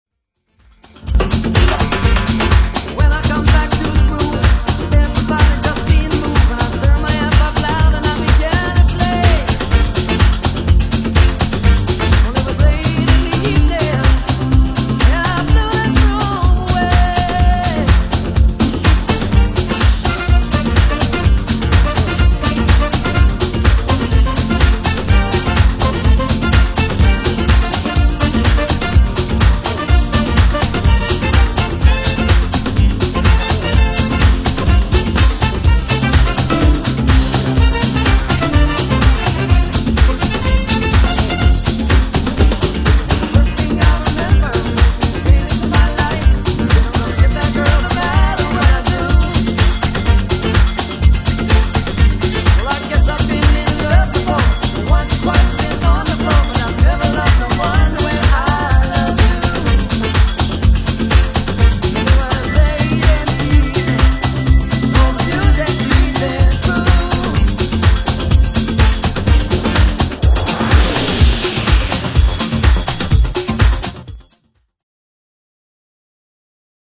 Latin